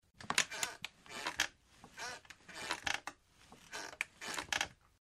Хороший способ покачивания в кресле